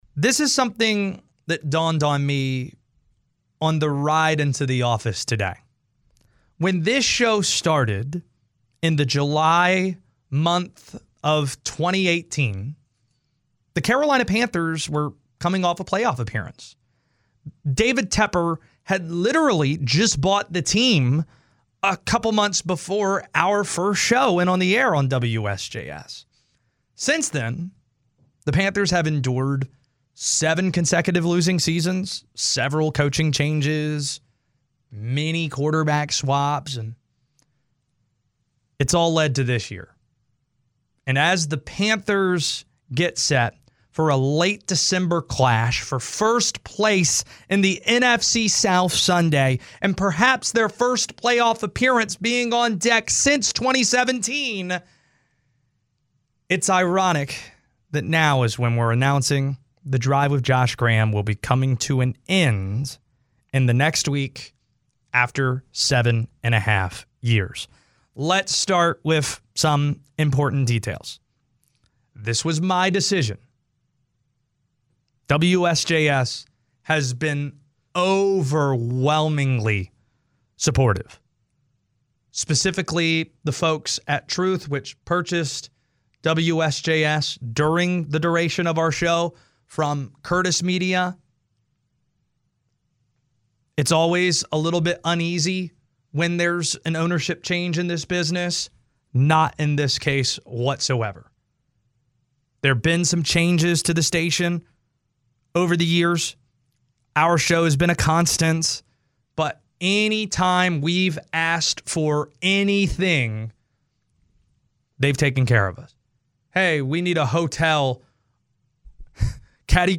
Carolina Panthers Sports Talk Radio WSJS NFC South ACC Tournament Triad Sports Sports Journalism